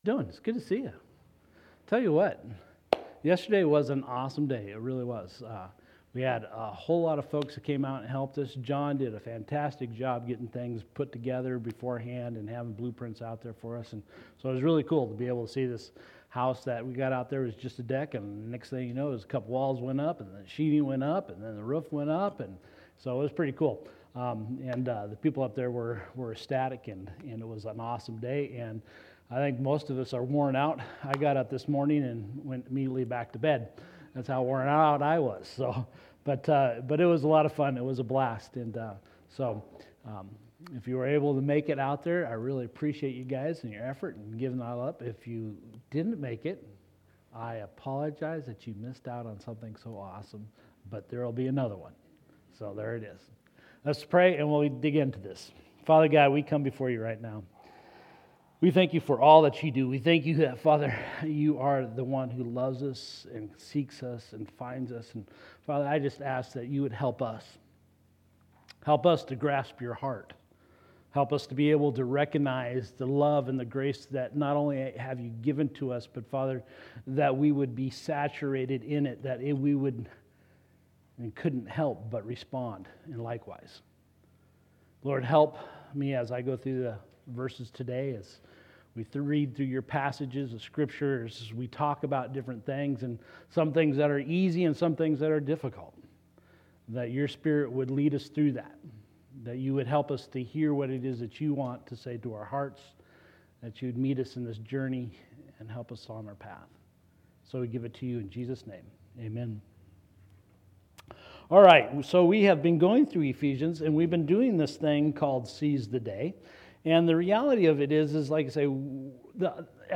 Sermons | Explore Church